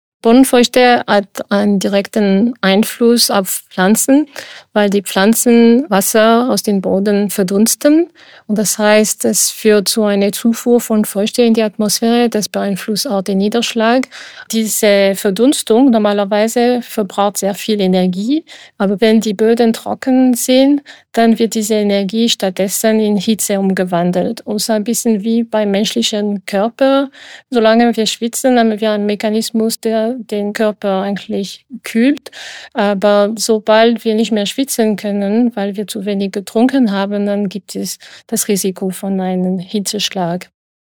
Frage an Prof. Dr. Sonia Seneviratne